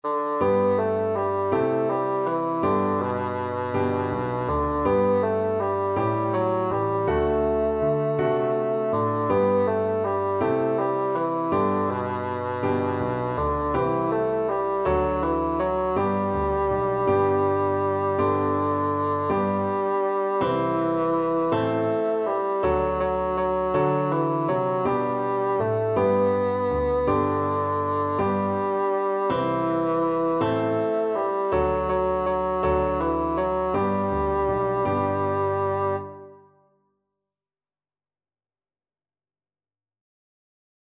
Traditional Trad. My Bonnie Lies over the ocean Bassoon version
Bassoon
G major (Sounding Pitch) (View more G major Music for Bassoon )
One in a bar . = c. 54
3/4 (View more 3/4 Music)
Traditional (View more Traditional Bassoon Music)
Scottish